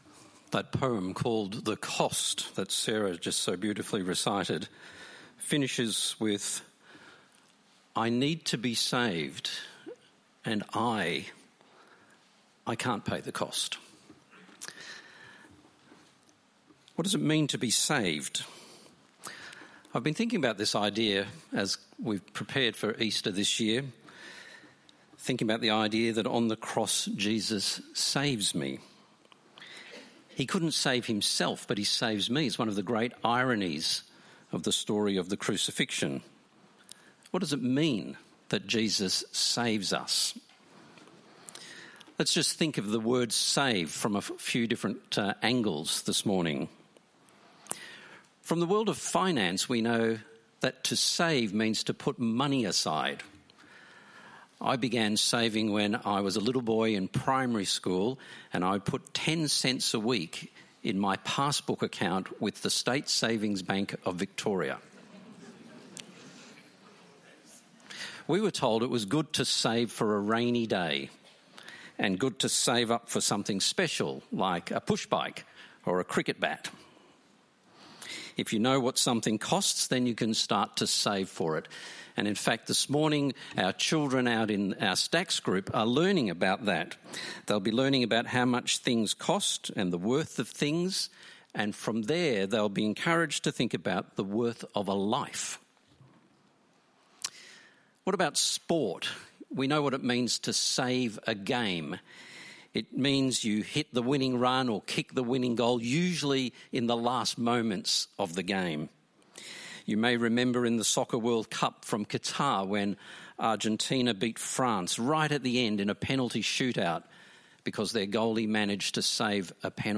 Download Download Bible Passage Matthew 27:1-66 In this sermon